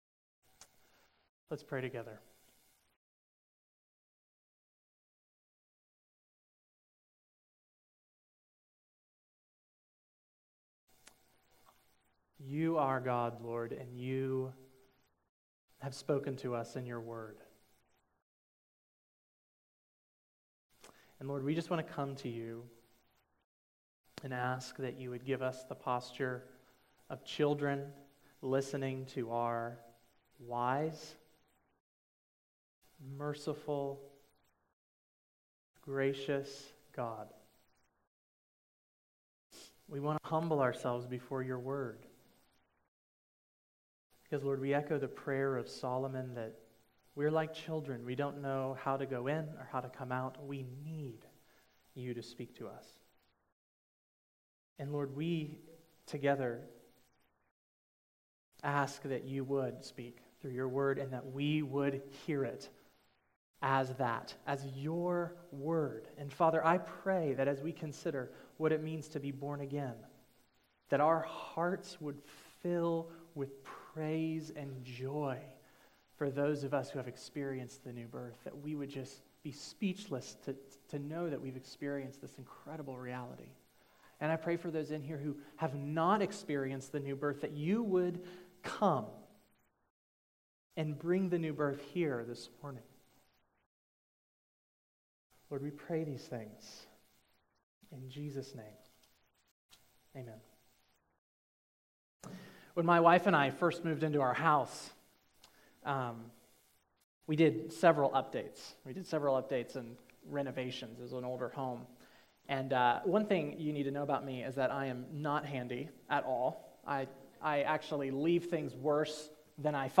January 22, 2017 Morning Worship | Vine Street Baptist Church